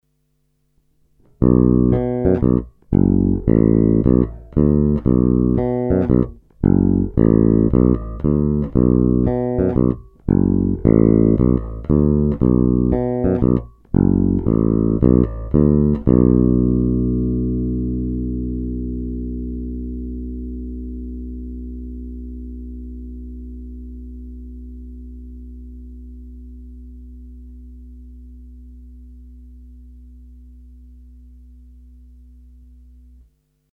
Není-li uvedeno jinak, následující nahrávky jsou vyvedeny rovnou do zvukové karty a kromě normalizace ponechány bez zásahů. Tónová clona byla vždy plně otevřená.
Snímač u kobylky